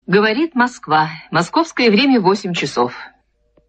Женский голос объявляет время Говорит Москва